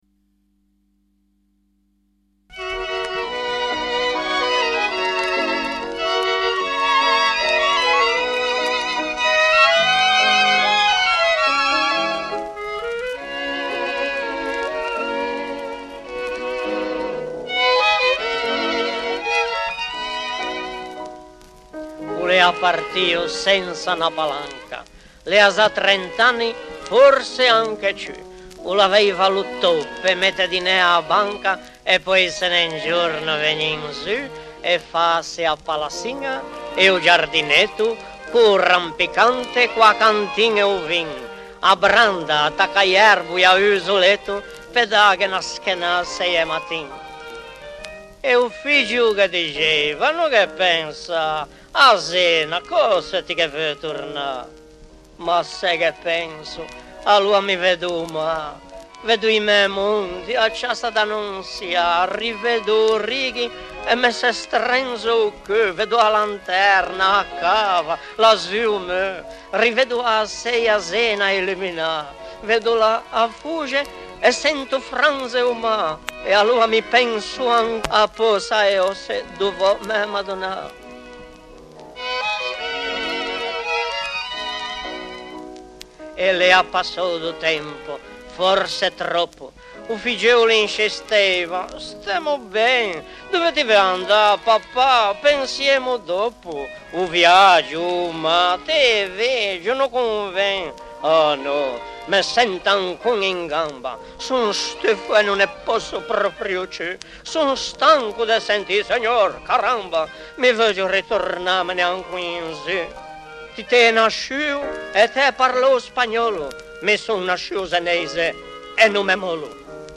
RACCOLTA DI CANTI TRADIZIONALI E SCENE COMICHE